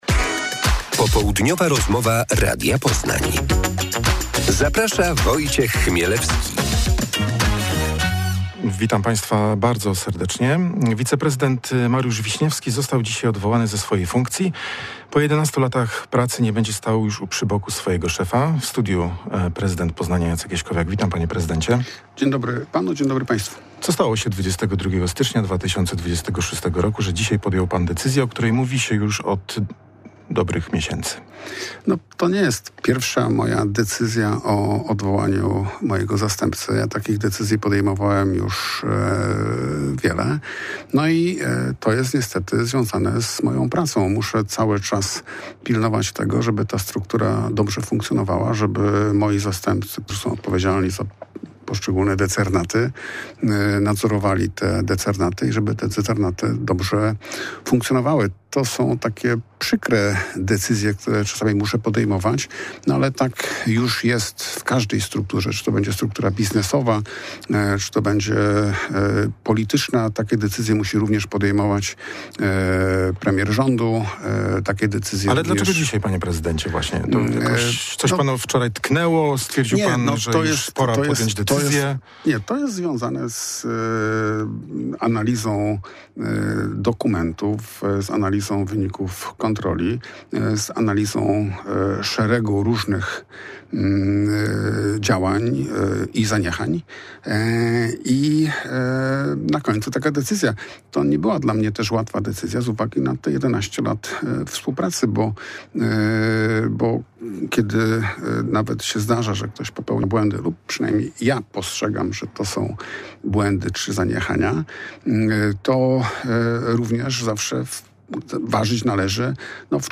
Popołudniowa rozmowa Radia Poznań – Jacek Jaśkowiak
pf5ak5i2zx62ric_popoludniowa-rozmowa-radia-poznan-jacek-jaskowiak.mp3